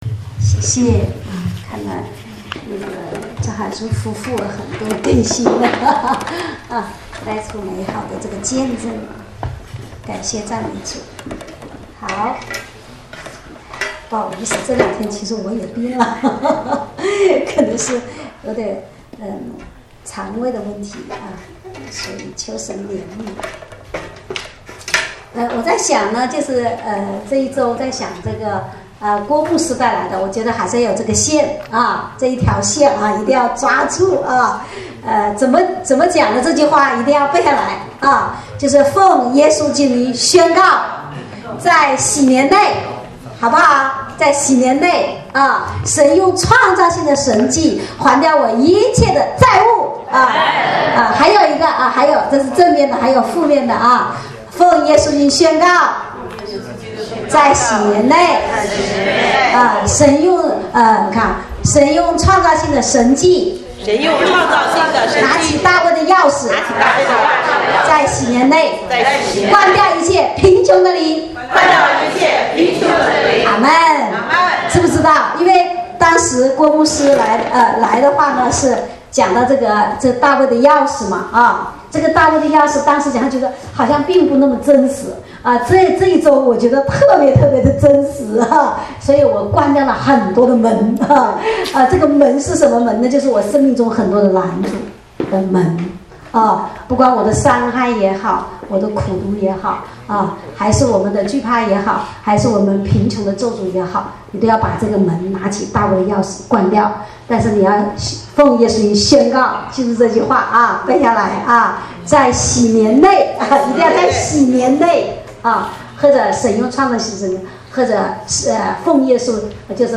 主日恩膏聚会录音